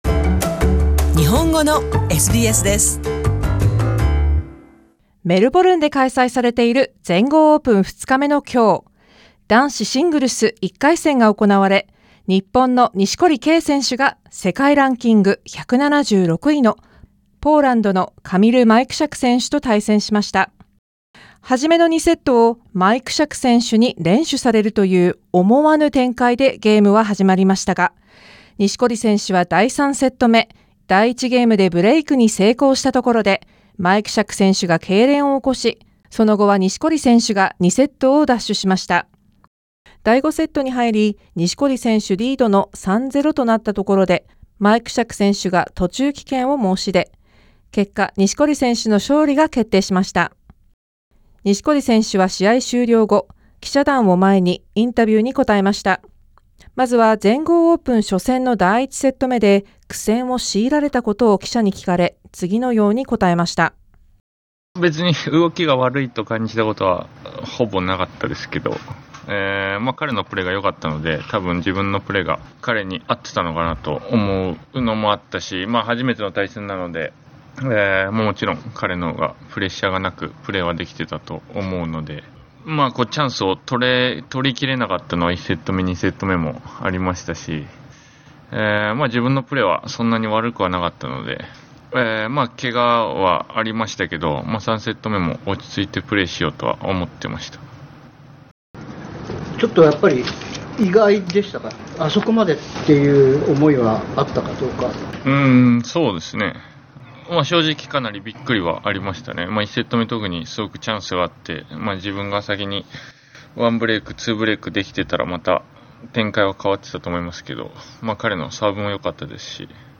Kei Nishikori of Japan plays against Kamil Majchrzak of Poland in the first round of the Australian Open in Melbourne on Jan. 15, 2019 Source: AAP Image/Kyodo via AP Images 錦織選手は試合終了後、日本人の記者団を前にインタビューに答えました。